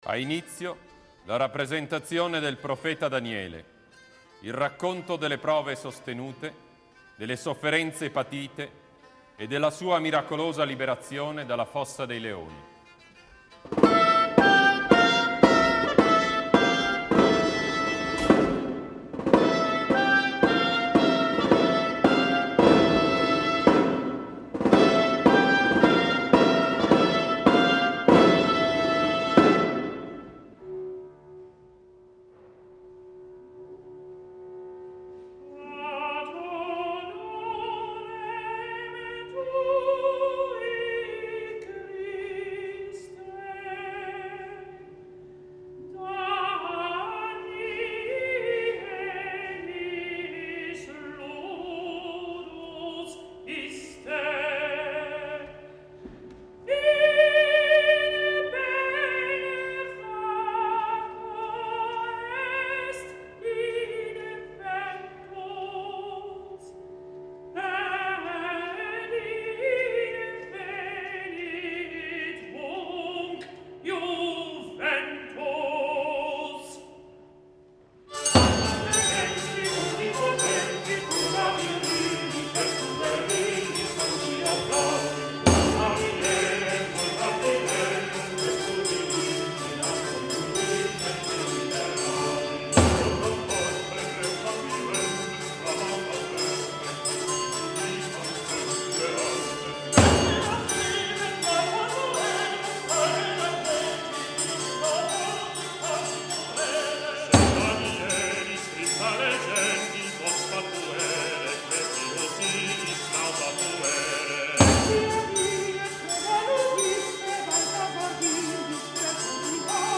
Il dramma trae l'argomento dall'Antico Testamento e mette in scena alcuni momenti topici della vita e delle opere di Daniele, considerato l'ultimo dei quattro profeti biblici. Per quanto riguarda gli aspetti formali la rappresentazione si avvicina al concetto di opera in musica ante litteram: nella composizione troviamo una grande varietà di melodie che abbracciano l'intero spettro della monodia medievale, l'impiego di molte forme musicali, un notevole numero di personaggi che agiscono sulla scena, grandi parti corali, abbondante impiego di metri...potremmo definirla una sorta di "grand opéra "medievale .